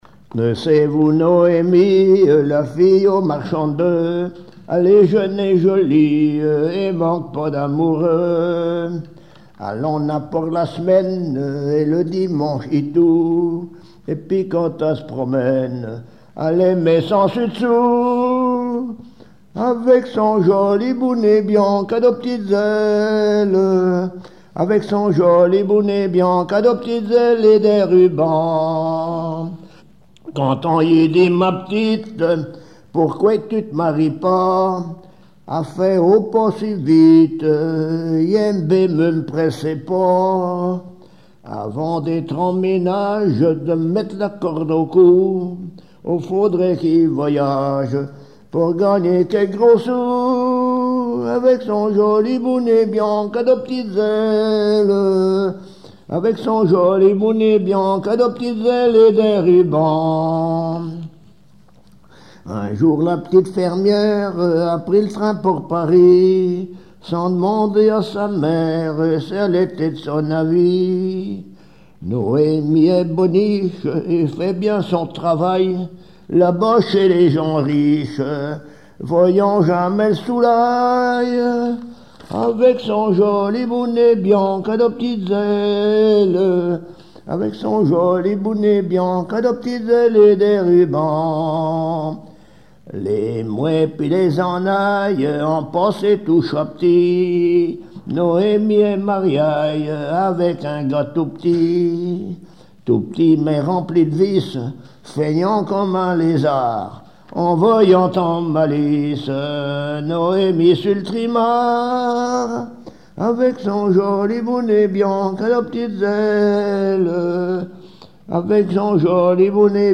chanson locales et traditionnelles
Pièce musicale inédite